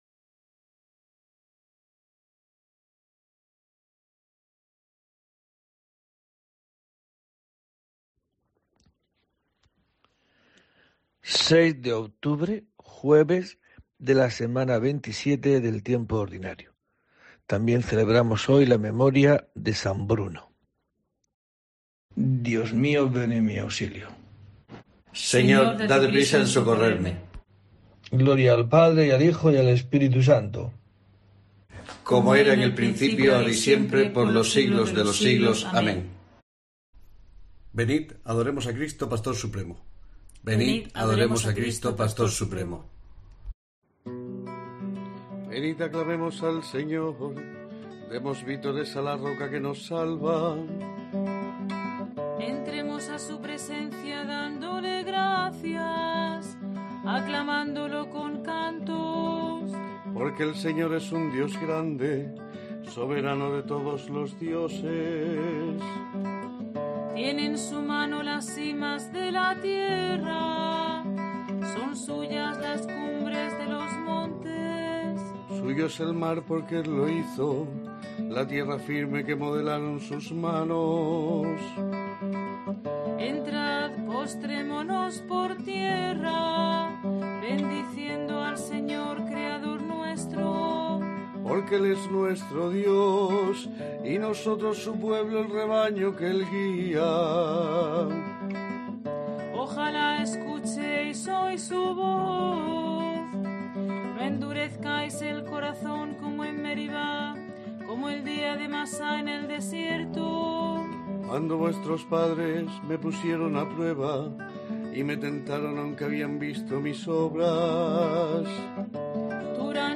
06 de octubre: COPE te trae el rezo diario de los Laudes para acompañarte